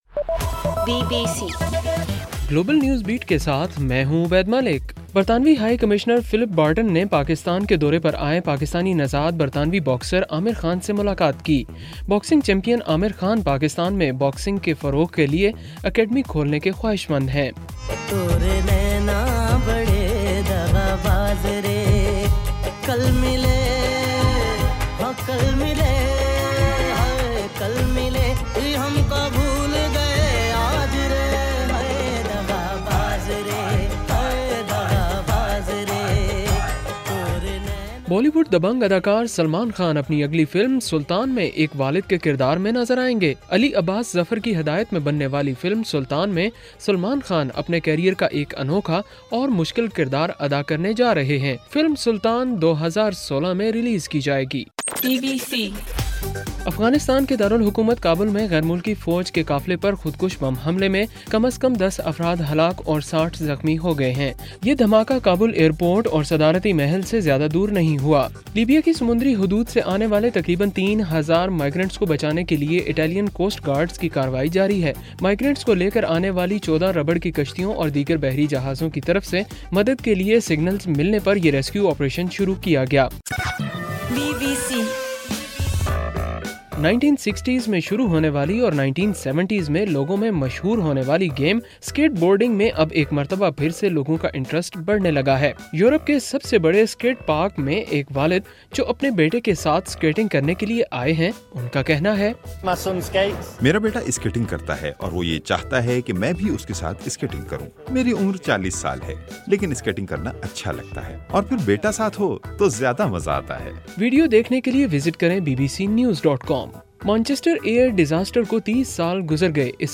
اگست 23: صبح 1 بجے کا گلوبل نیوز بیٹ بُلیٹن